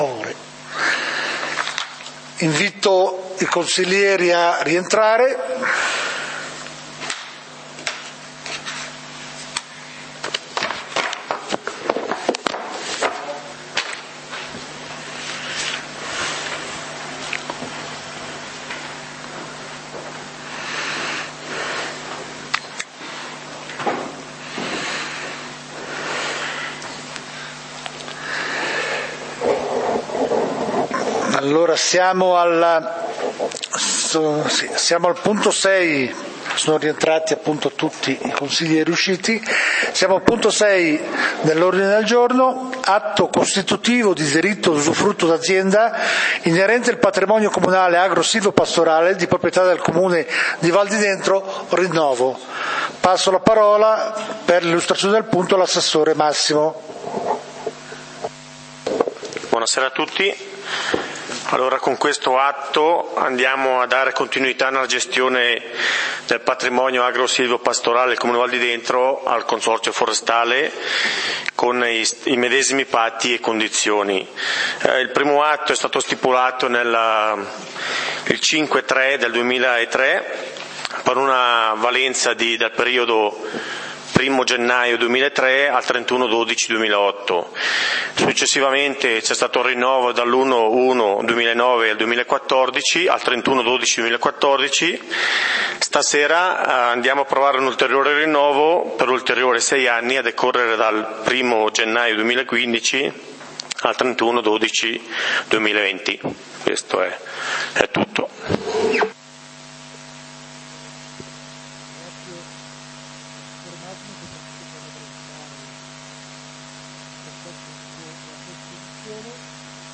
Consiglio comunale di Valdidentro del 18 Dicembre 2014